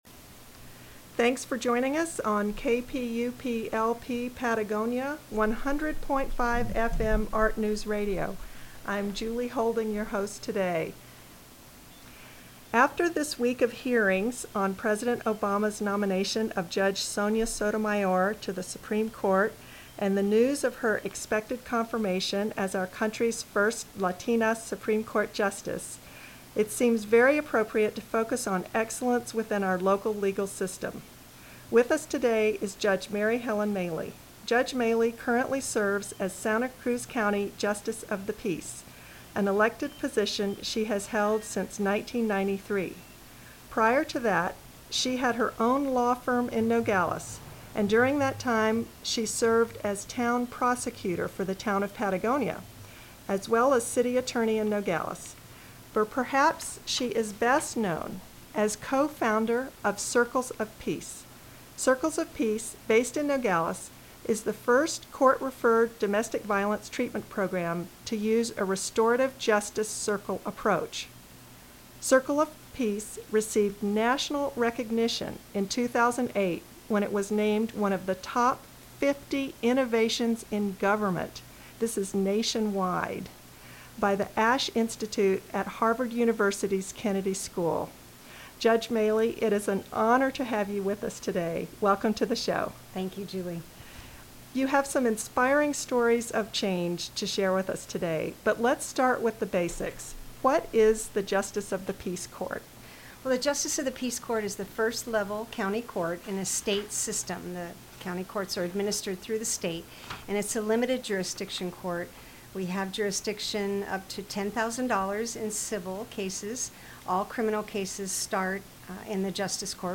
Click to listen to Judge Mary Halen Maley’s interview